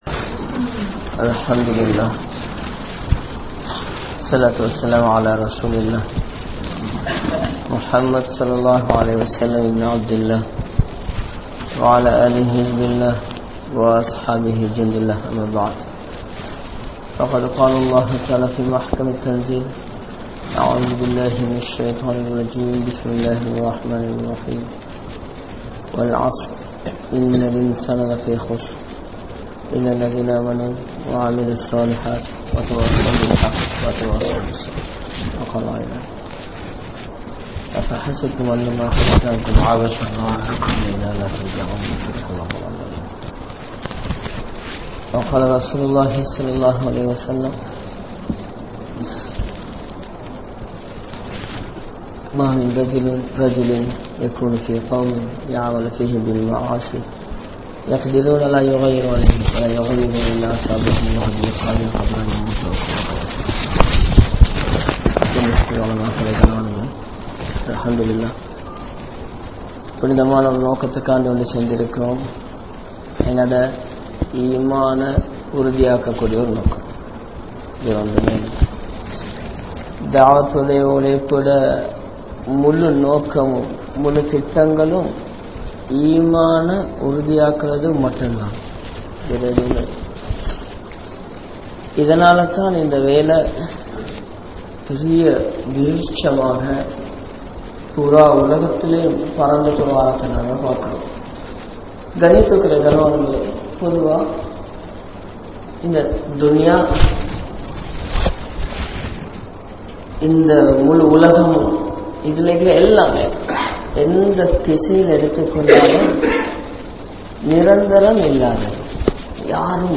Nimmathi Illaatha Manitharhal (நிம்மதி இல்லாத மனிதர்கள்) | Audio Bayans | All Ceylon Muslim Youth Community | Addalaichenai